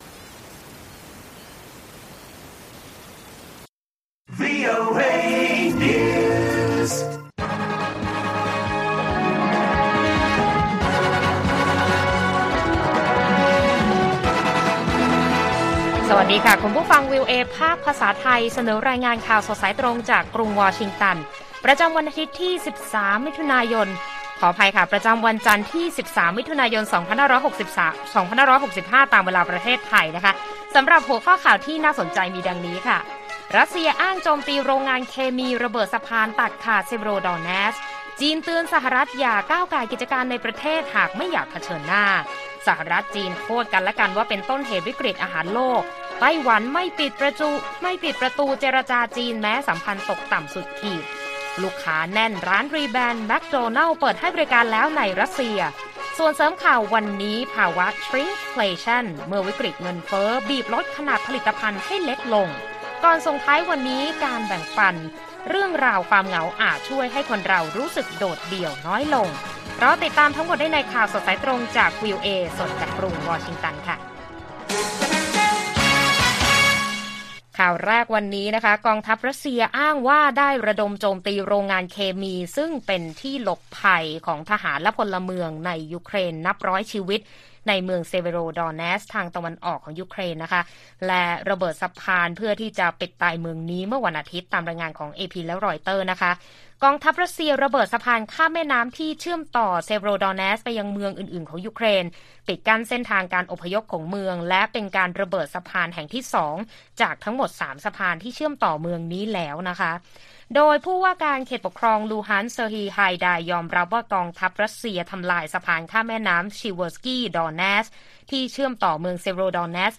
ข่าวสดสายตรงจากกรุงวอชิงตัน วันจันทร์ ที่ 13 มิถุนายน 2565